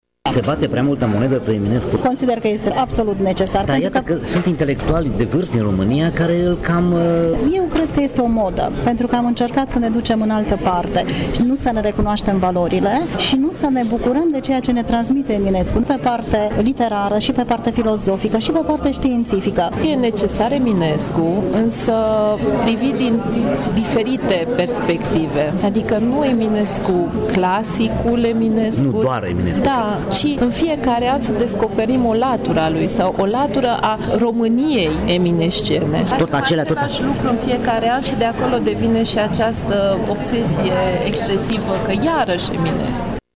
La rândul lor, profesorii spun că studiul lui Eminescu ar trebui privit și din alte perspective, mai moderne: